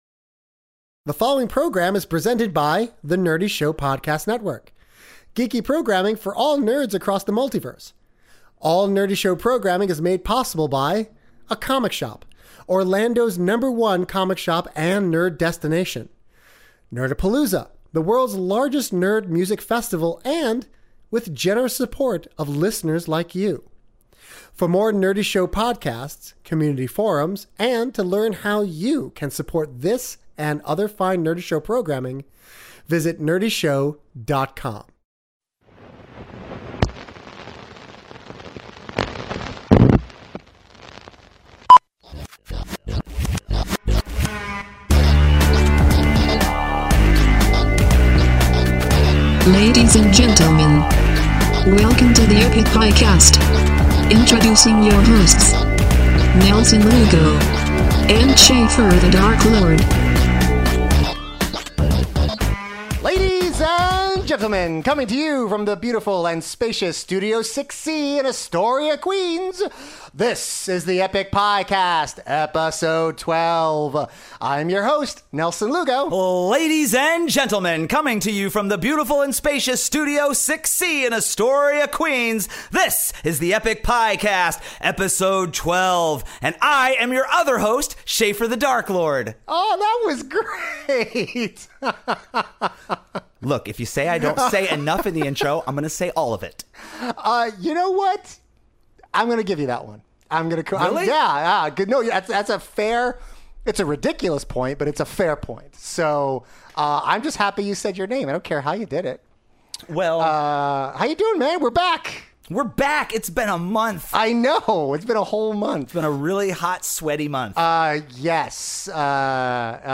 Then, with no scheduled guest, they interview EACH OTHER!